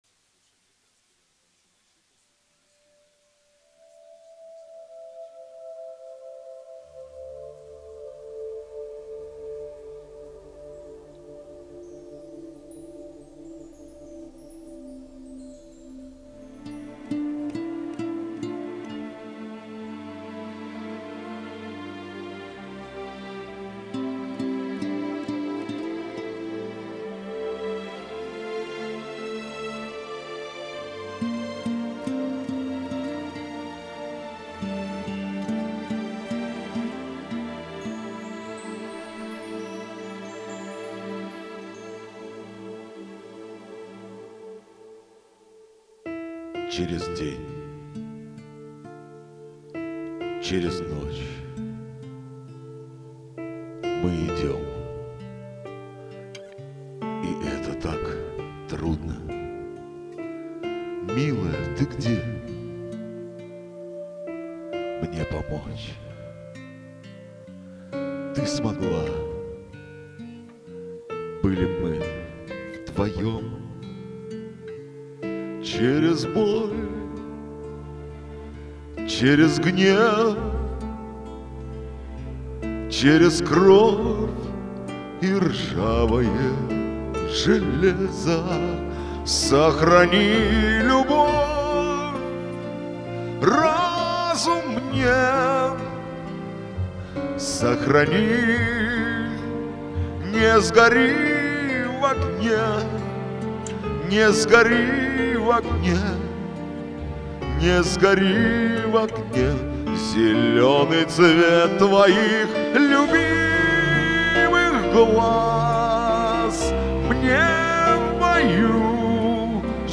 Удиветельно так похож тембр голоса